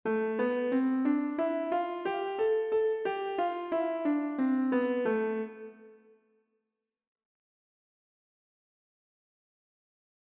＜Ａナチュラルマイナースケール＞